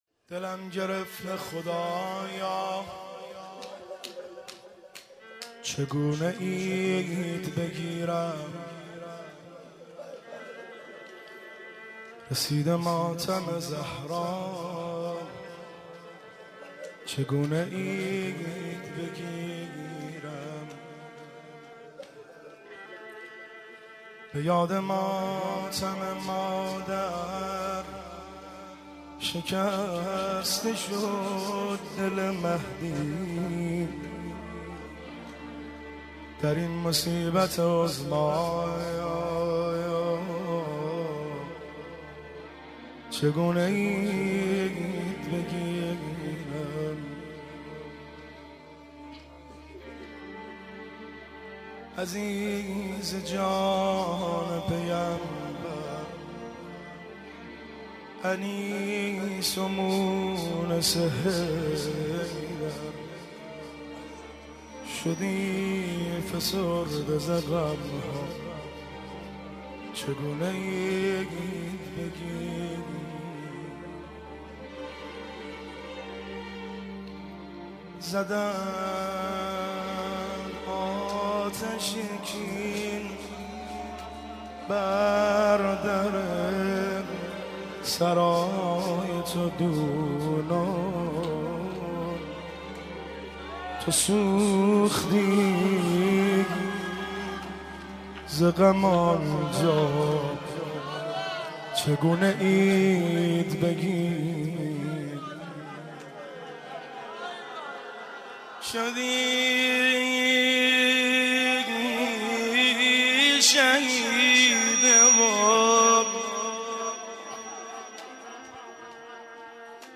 دانلود مداحی فاطمیه
روضه خوانی، شهادت حضرت فاطمه زهرا(س